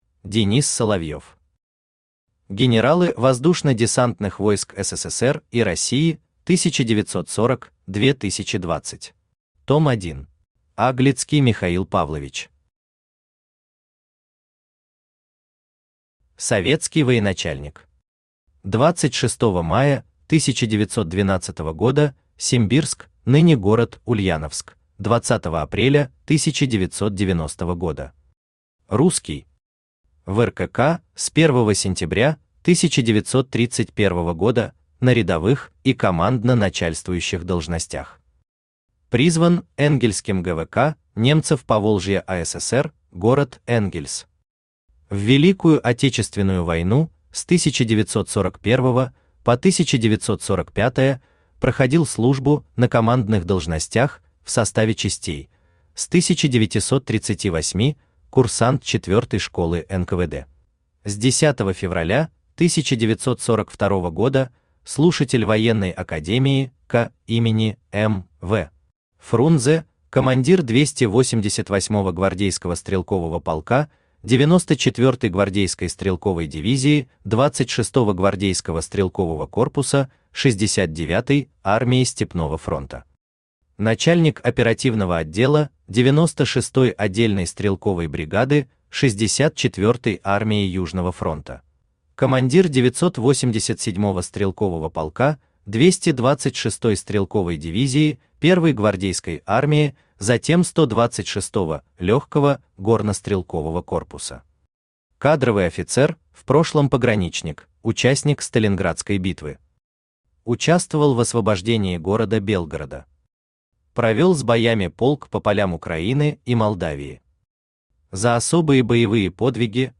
Аудиокнига Генералы Воздушно-десантных войск СССР и России 1940-2020. Том 1 | Библиотека аудиокниг
Том 1 Автор Денис Соловьев Читает аудиокнигу Авточтец ЛитРес.